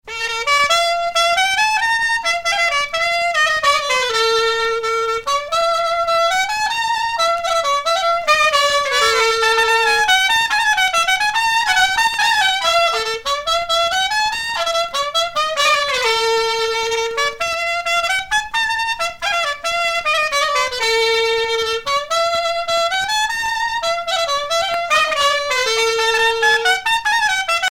danse : fisel (bretagne)
Sonneurs de clarinette